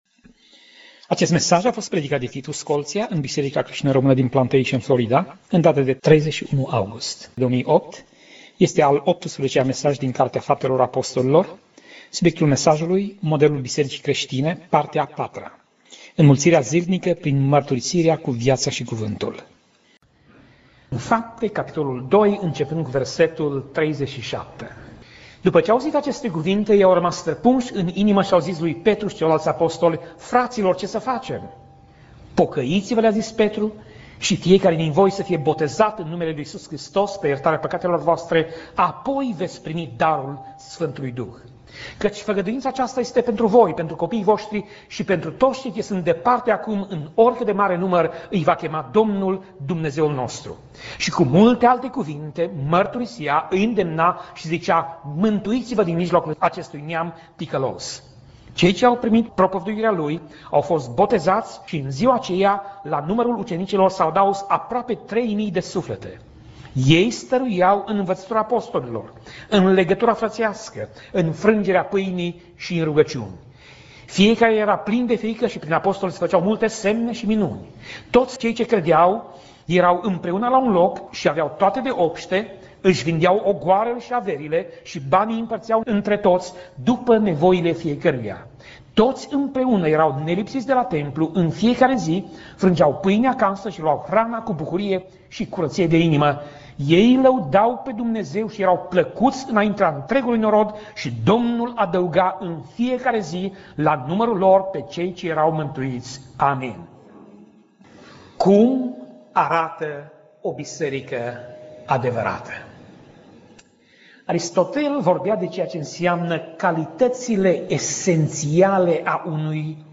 Pasaj Biblie: Faptele Apostolilor 2:41 - Faptele Apostolilor 2:47 Tip Mesaj: Predica